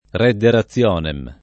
vai all'elenco alfabetico delle voci ingrandisci il carattere 100% rimpicciolisci il carattere stampa invia tramite posta elettronica codividi su Facebook redde rationem [lat. r $ dde ra ZZL0 nem ] locuz. m. (in it.)